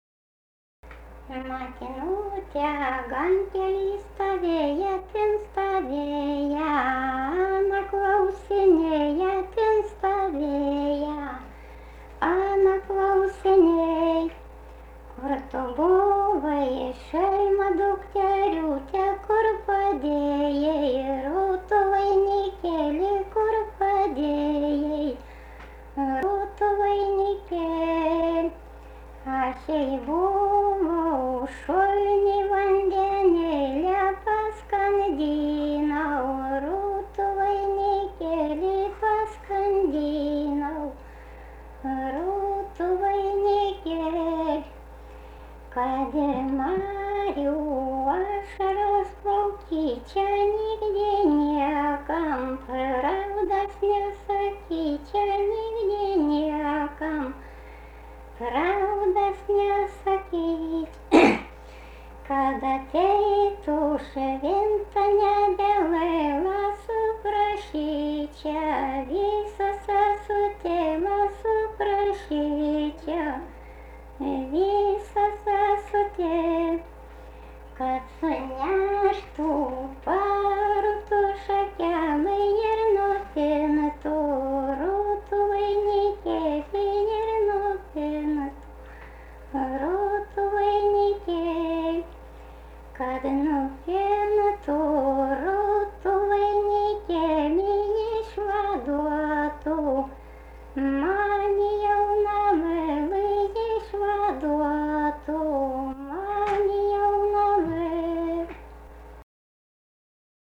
daina, vestuvių
Atlikimo pubūdis vokalinis